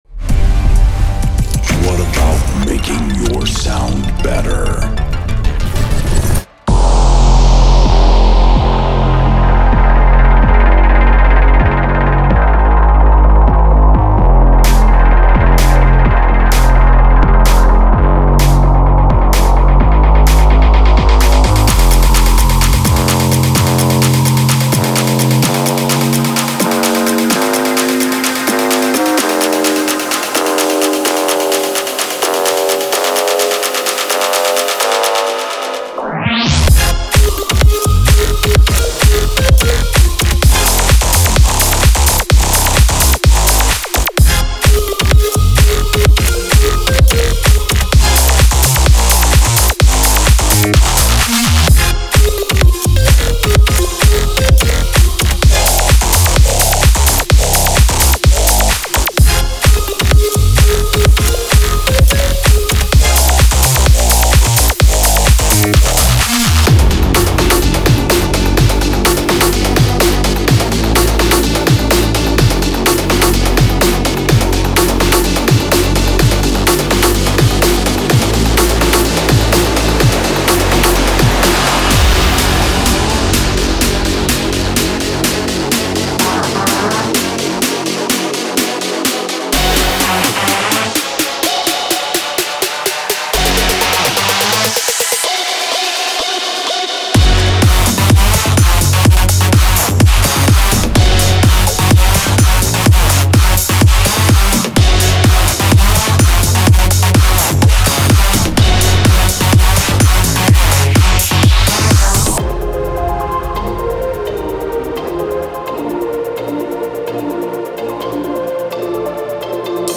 上升，下降，突然跳动，爆裂和弹出的效果。
50 Synth Shots
30 Percussion Loops
23 FX